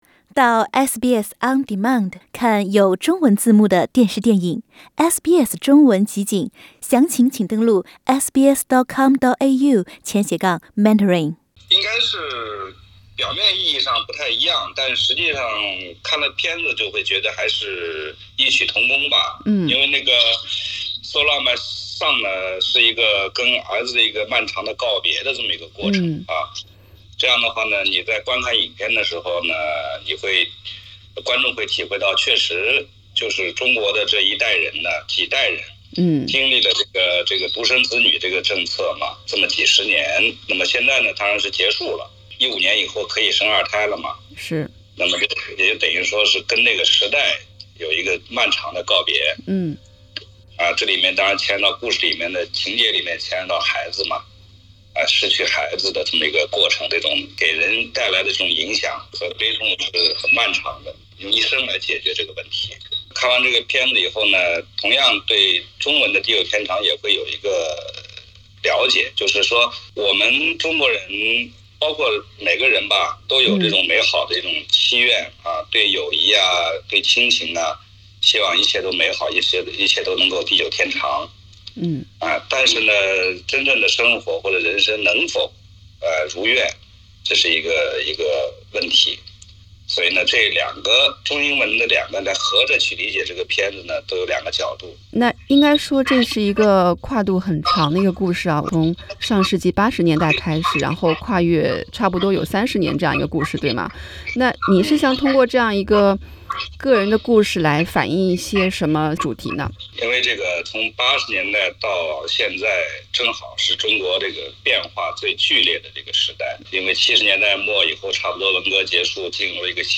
【专访】王小帅谈《地久天长》：与计划生育时代的漫长告别